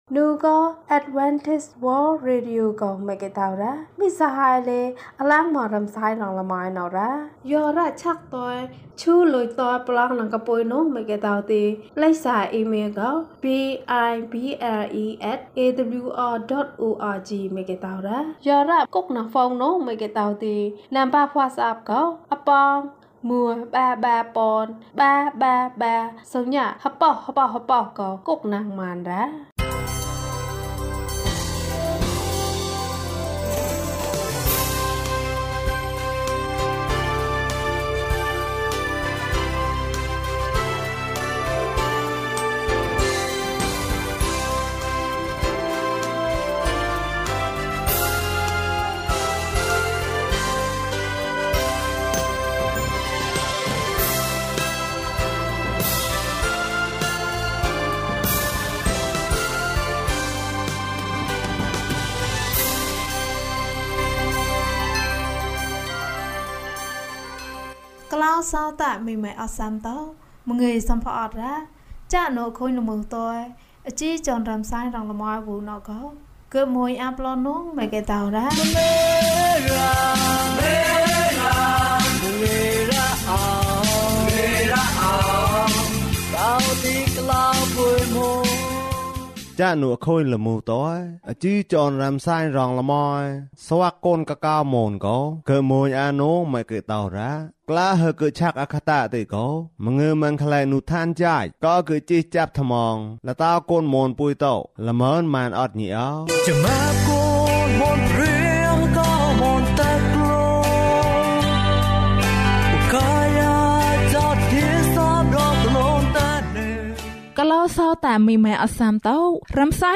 ခရစ်တော်ထံသို့ ခြေလှမ်း။၄၉ ကျန်းမာခြင်းအကြောင်းအရာ။ ဓမ္မသီချင်း။ တရားဒေသနာ။